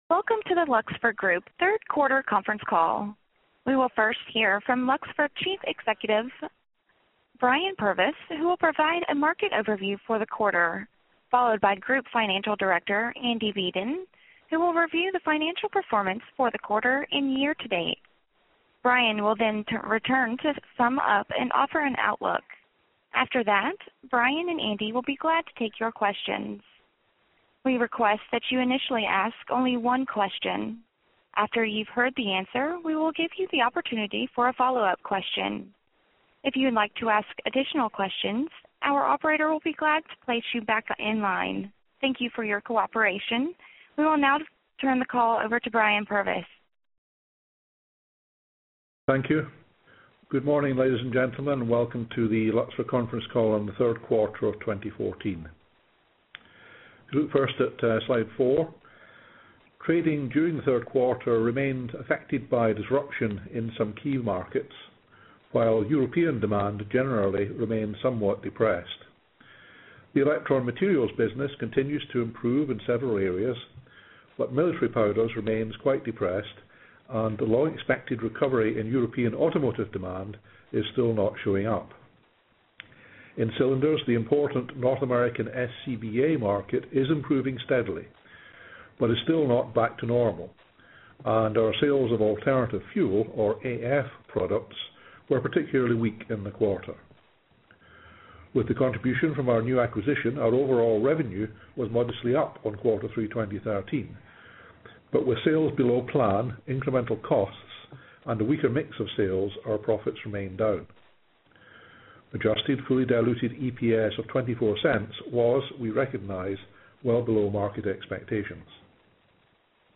2014 Q3 Conference Call Audio (Opens in a new browser window)